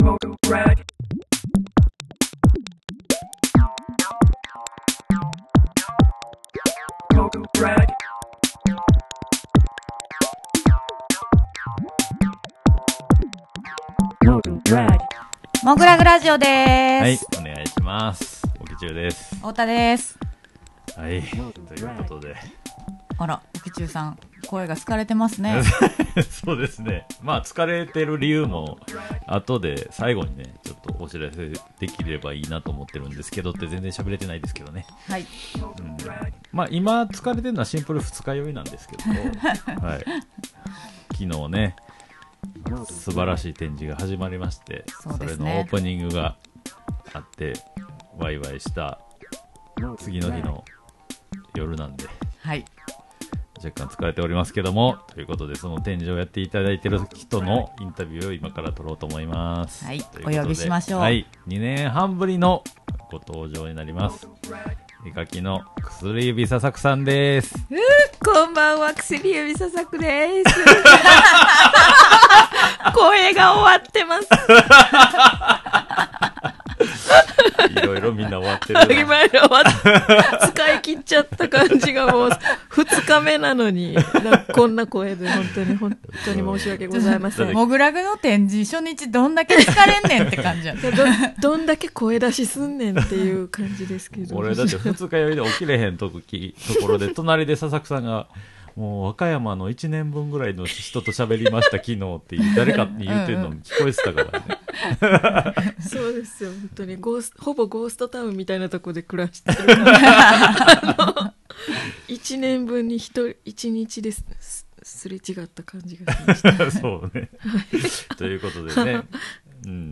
アーティストインタビュー